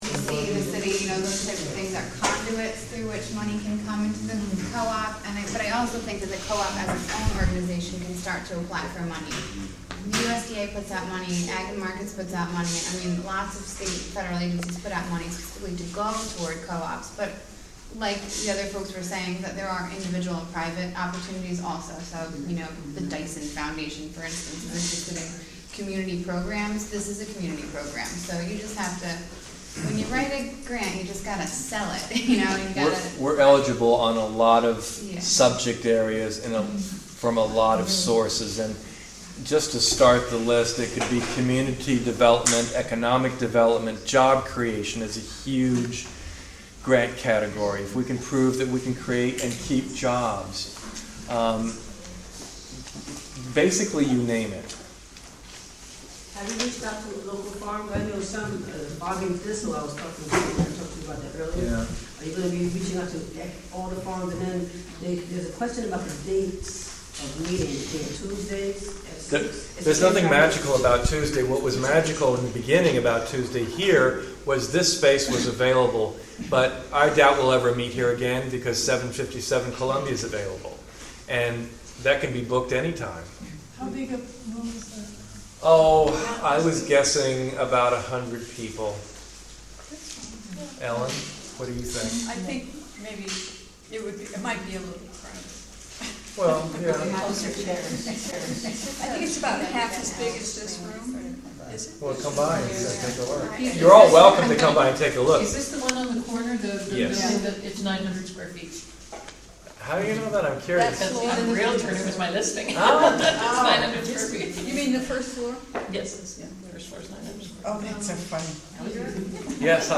Part two of Acres Co-Op Market Listening Meeting and Community Forum (Audio)